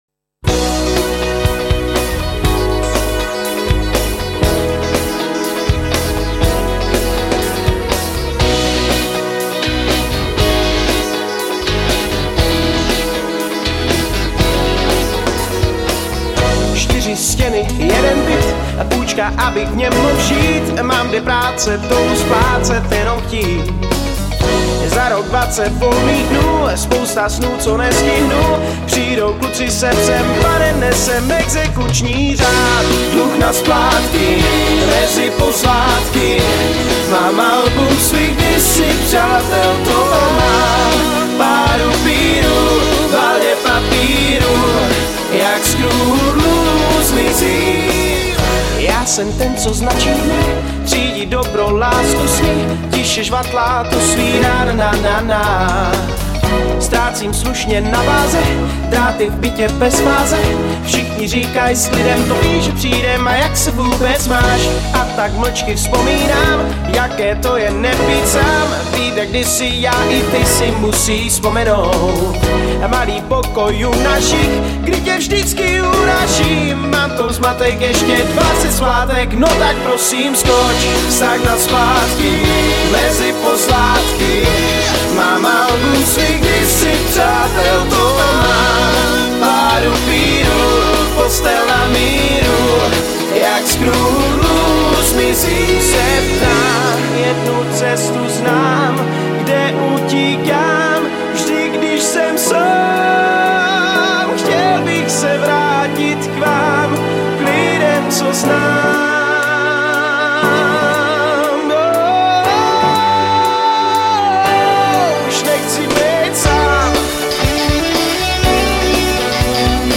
Žánr: Pop
CD bylo nahráno ve známém ostravském studiu Citron.